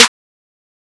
Cardiak Snare 2 (SNARE).wav